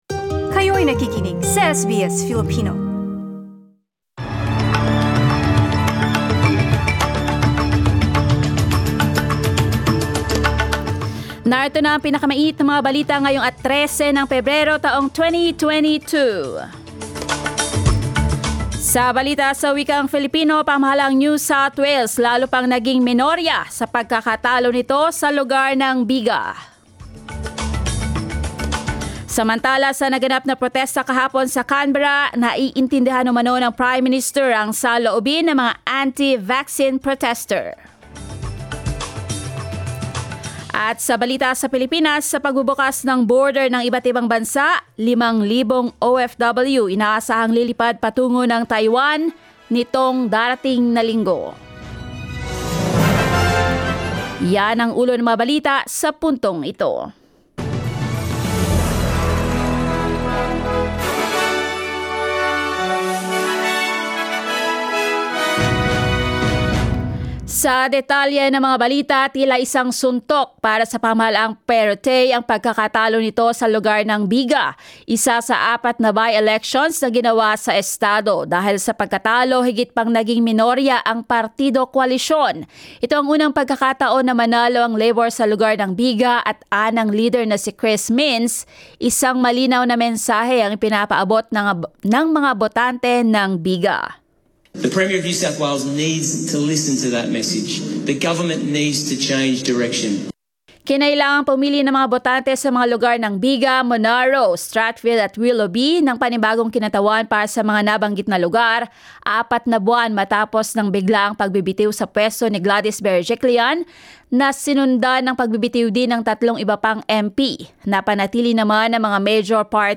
SBS News in Filipino, Sunday 13 February